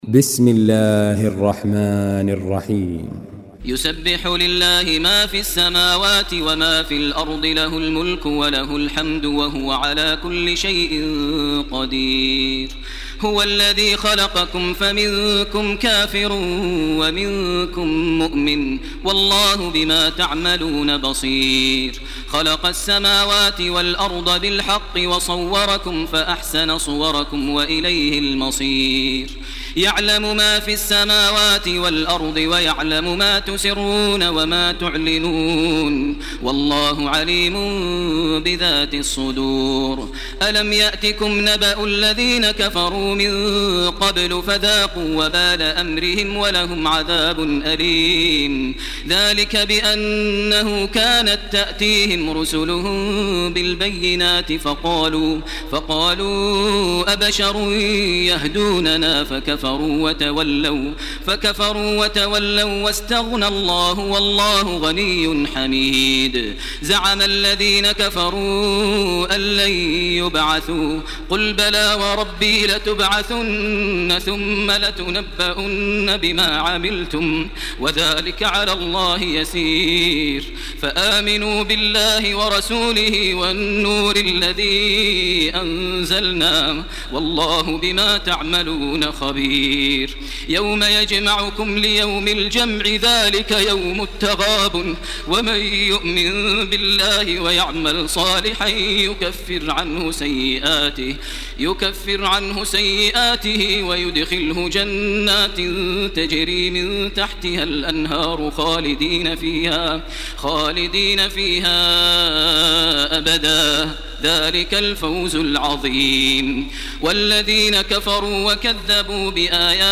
Surah التغابن MP3 by تراويح الحرم المكي 1429 in حفص عن عاصم narration.
مرتل